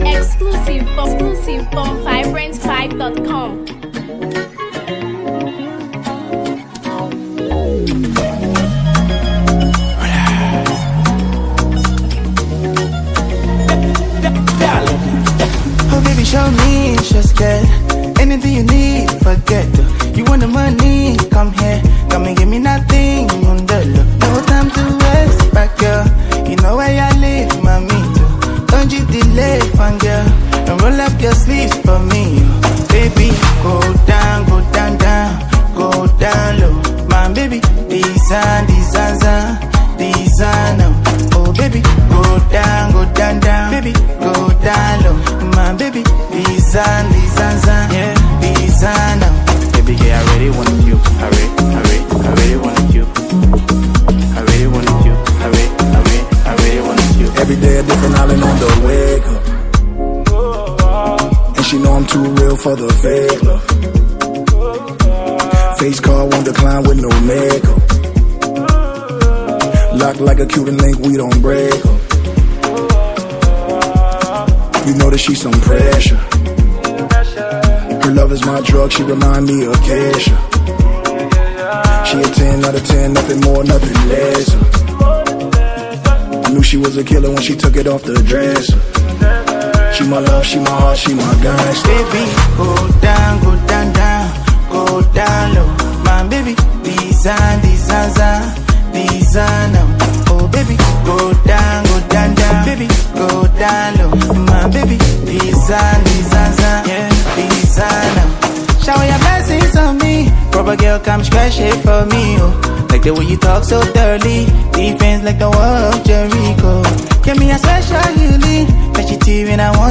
delivering infectious melodies and vibrant energy
• Seamless fusion of Afrobeats, hip-hop, and street soul
• High-quality production, sharp vocals, and replay value